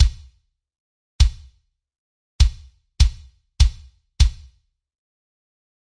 循环 " 金属循环
描述：在一个1000升的空罐子上打了三下，投出了一个有节奏的循环
标签： 低音 rythmic rythmn 金属 混响 击败 金属
声道立体声